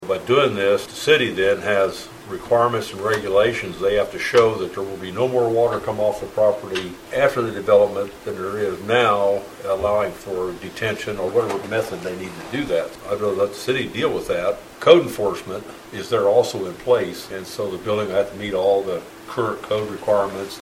Commissioner Ron Wells explains why he was supporting the resolution.